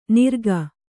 ♪ nirga